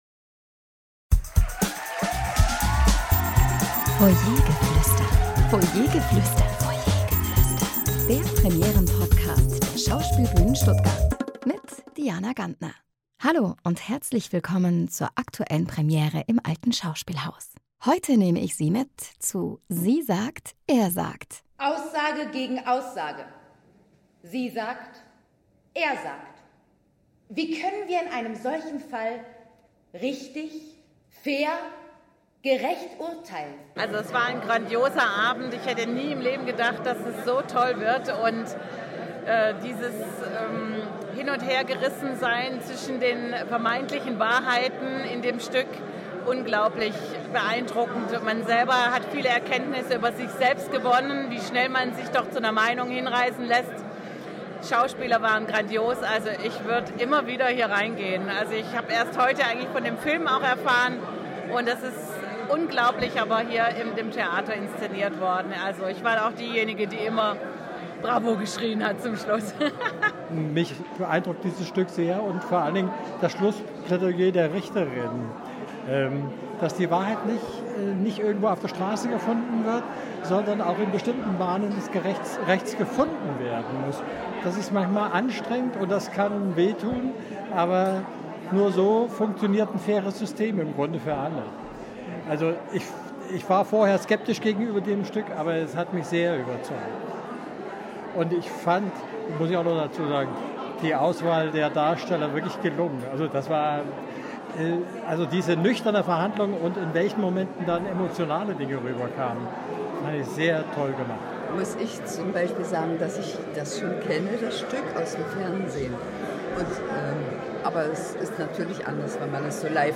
Publikumsstimmen zur Premiere von “Sie sagt. Er sagt.”